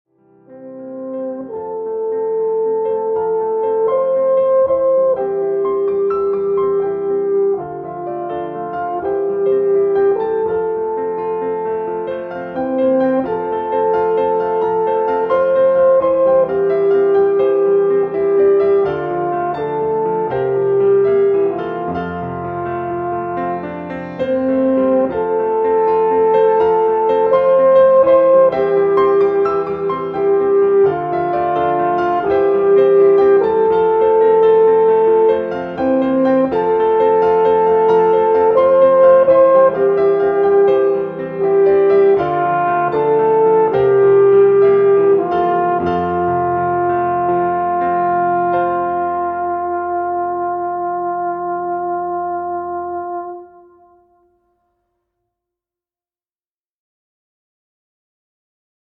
Kompositionen Alphorn (Solo bis Trio) mit Klavier